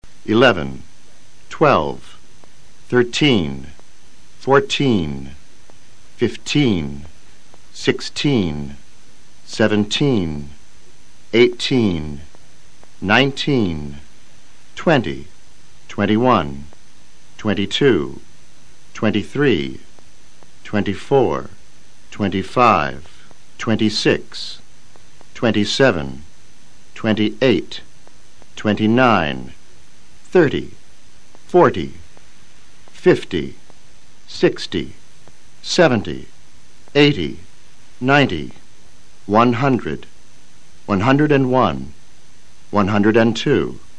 Escucha al profesor y luego trata de repetir los NUMEROS simultáneamente.
puedes decir one hundred (uán jándrid) o también a hundred (a jándrid).